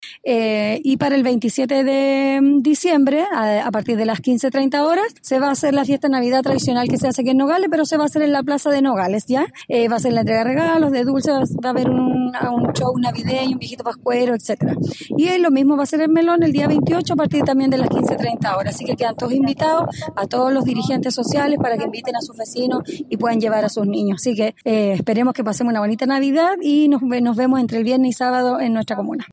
La alcaldesa Leslie Pacheco Ramírez invitó a toda la comunidad a participar de esta fiesta navideña:
cuna-Alcaldesa-Leslie-Pacheco-x-navidad-2024.mp3